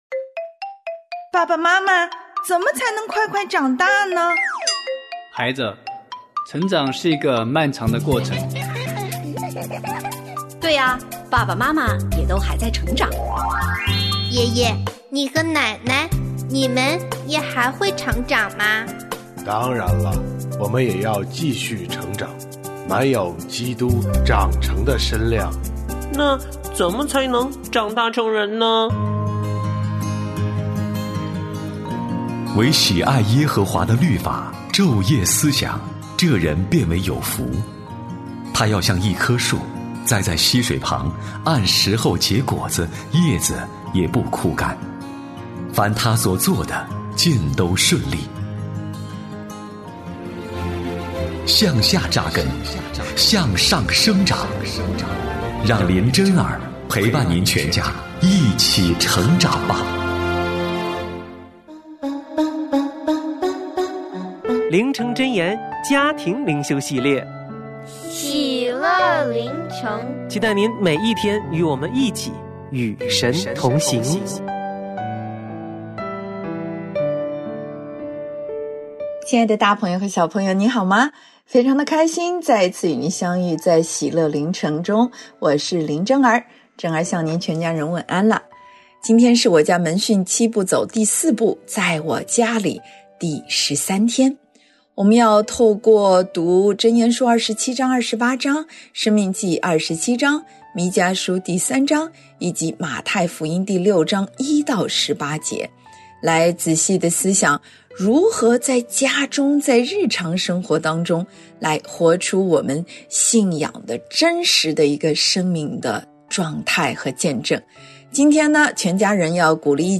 我家剧场：圣经广播剧（105）大卫王立所罗门为王；亚多尼雅害怕求饶